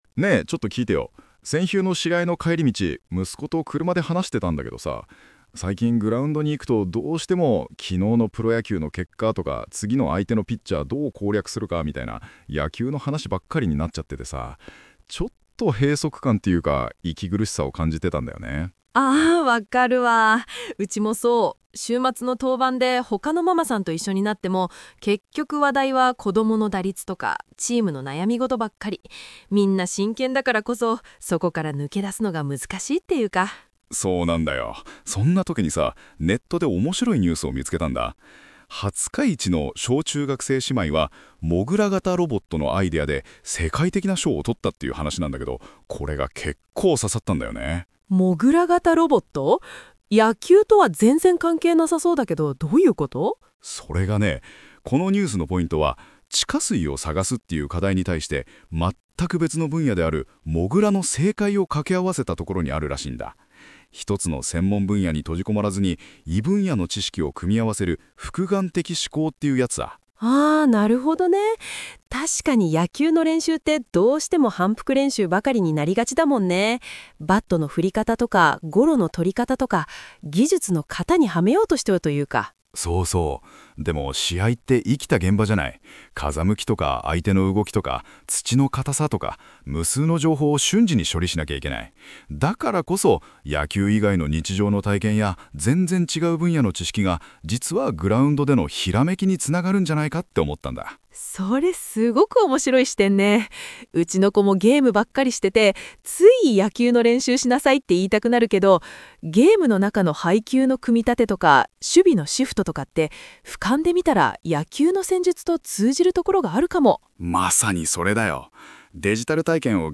※AI生成による音声コンテンツにて、発音や読み方に違和感ございますが、ご了承ねがいます。